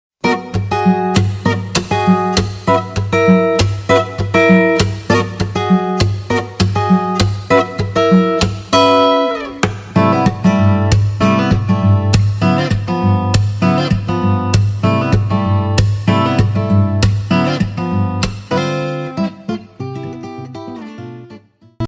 このアコギ名手が奏でるジャパニーズ手工ギターをコンセプトにしたアルバム